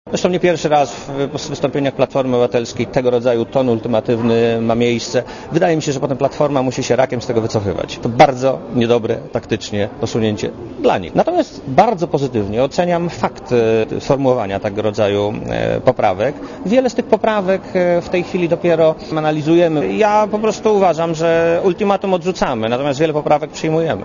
* Mówi premier Marek Belka*